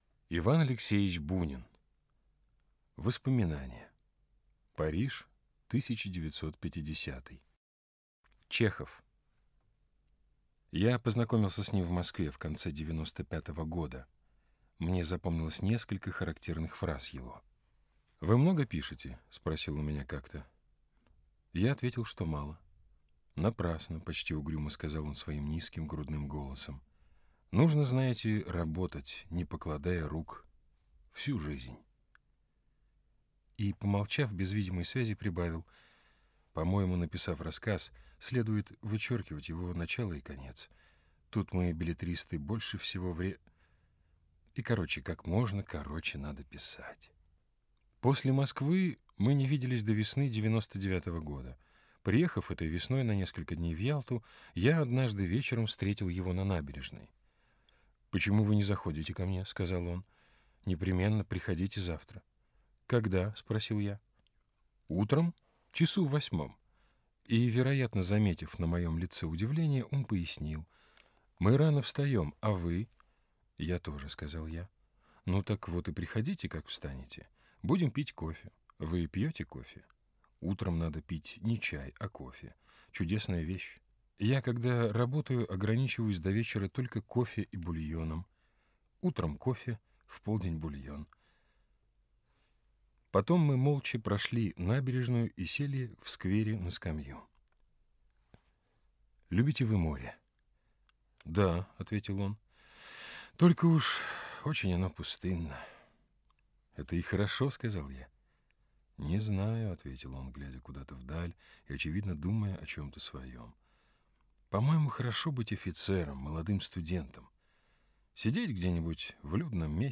Аудиокнига Чехов | Библиотека аудиокниг